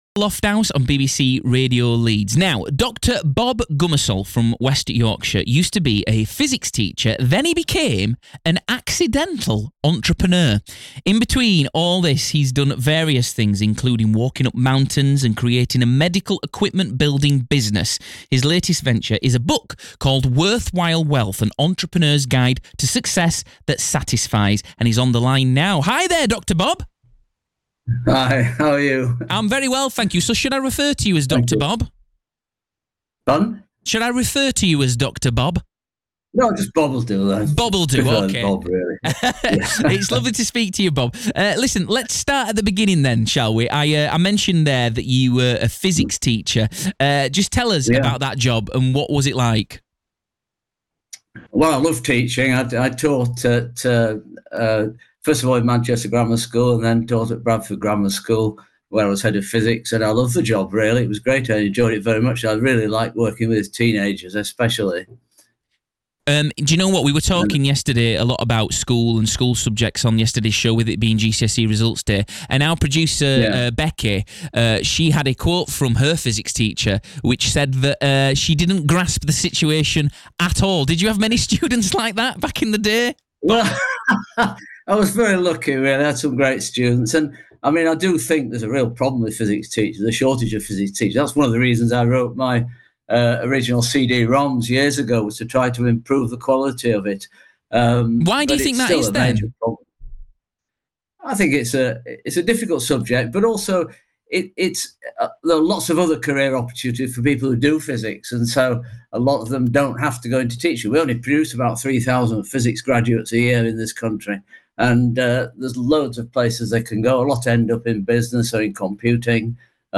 BBC Radio Leeds interview 23/08/2024